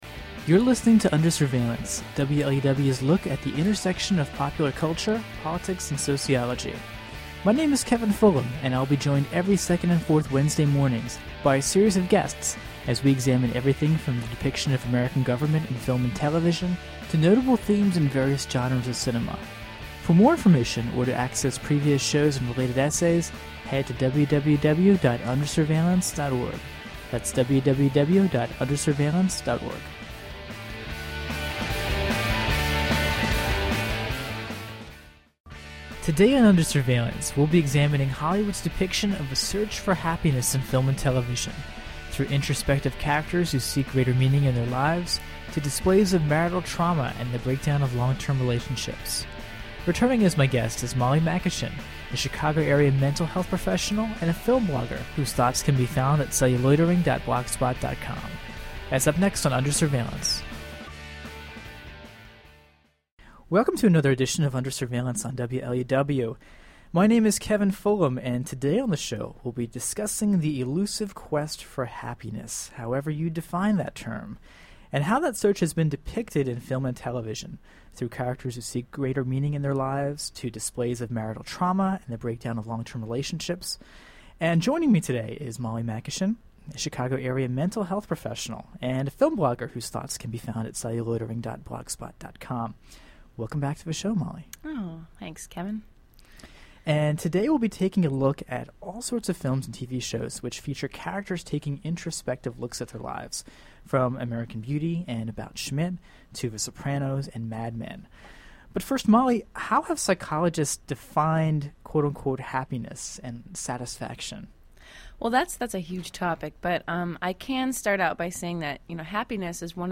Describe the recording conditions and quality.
[Originally broadcast on WLUW’s Under Surveillance in April 2009.] https